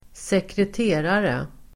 Uttal: [²sekret'e:rare]